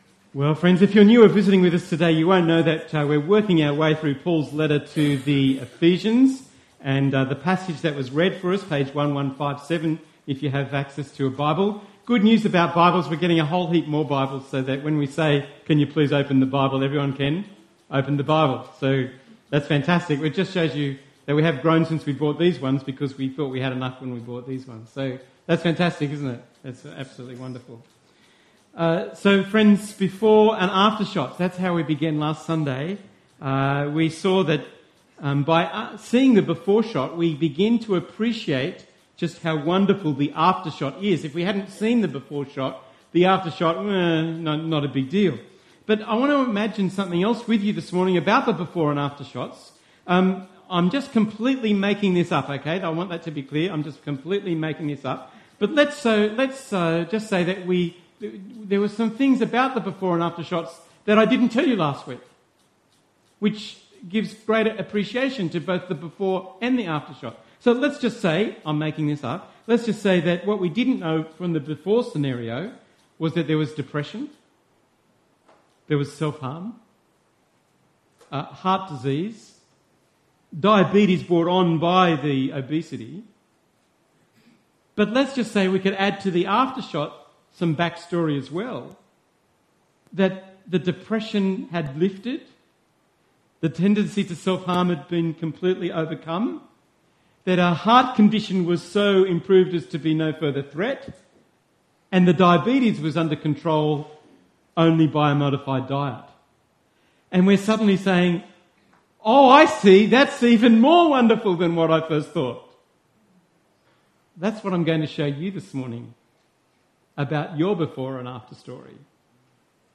The third sermon in our series “Living (the) Gospel” from Paul’s letter to the Ephesians. Today’s passage: Ephesians 2:11-22. Audio recorded at our Tewantin service.